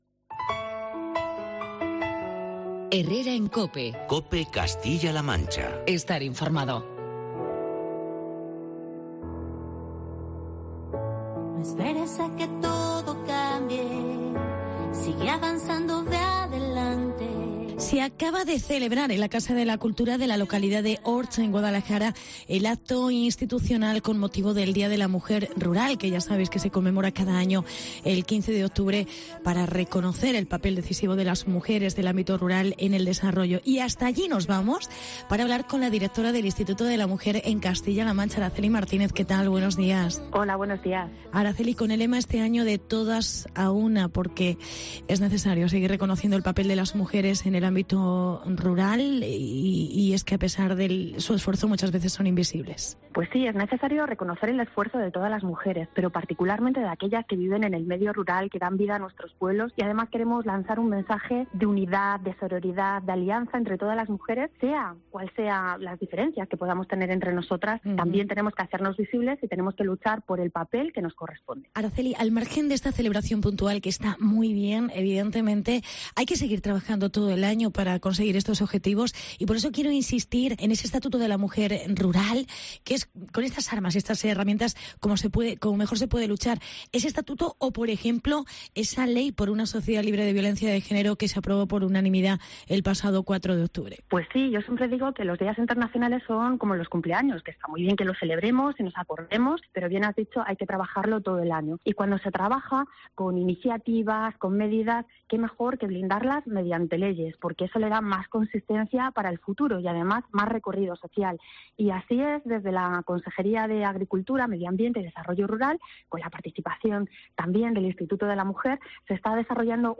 Entrevista con Araceli Martínez. Directora Instituto de la Mujer de CLM